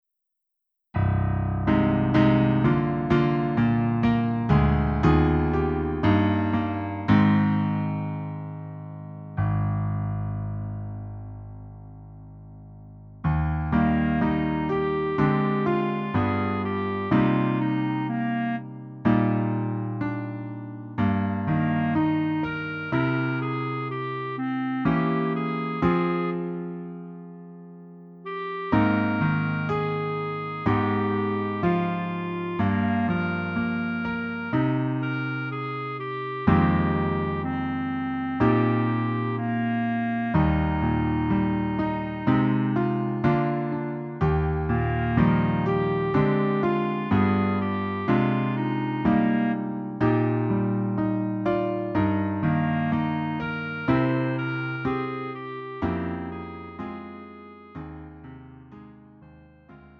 음정 -1키 4:11
장르 구분 Lite MR